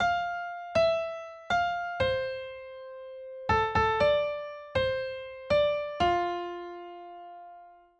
少しのメロディの断片を思いついたので、Logic Proに入れてみる。
曲のキーはF メロディを入れてみて気づいたんだけど、 この曲のキーはFになってました。